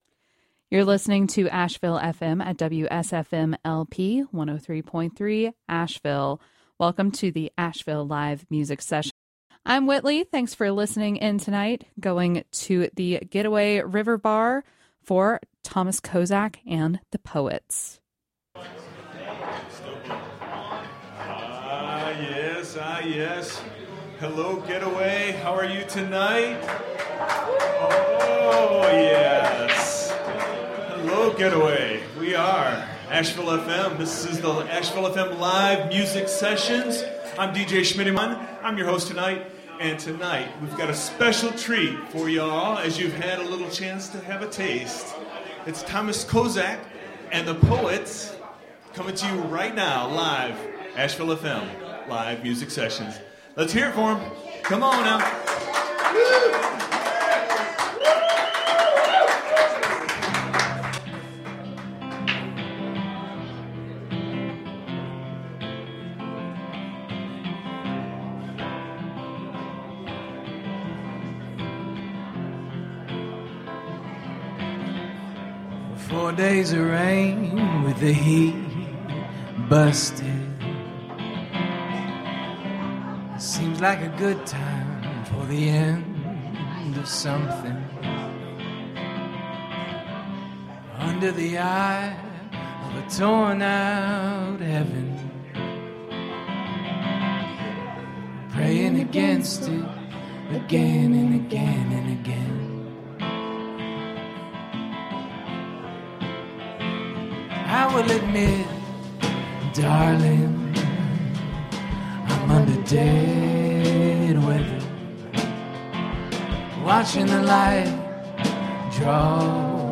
Live from The Getaway River Bar
Explicit language warning
vocals
keys / drums
vocals, guitar, harmonica